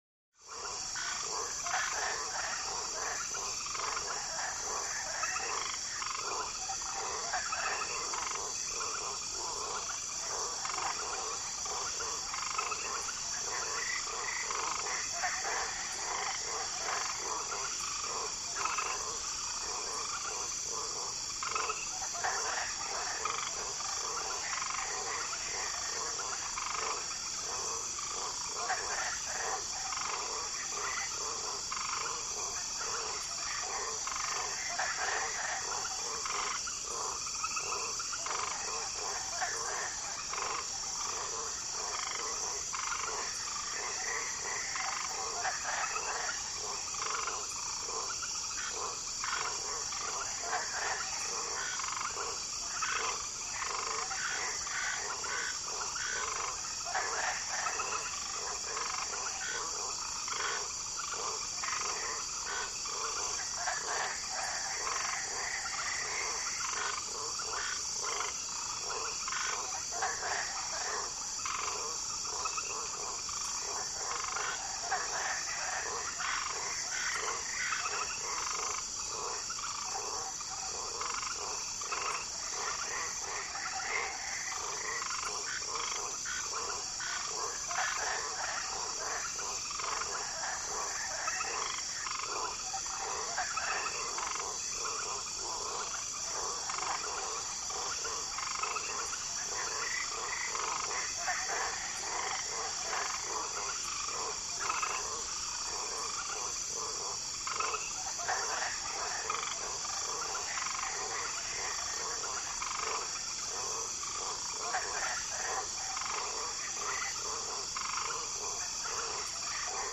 Steady Exotic Bird Calls, Insect Buzz In Guatemalan Rain forest, Busy.